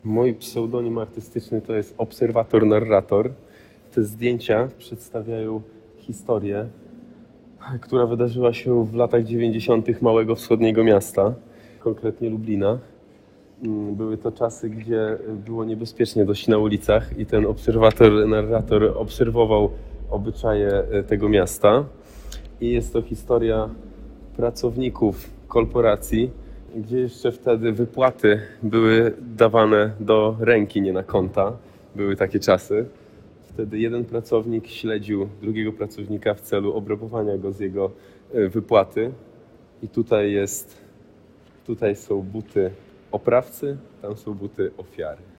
Intervention: Audioguide in Polish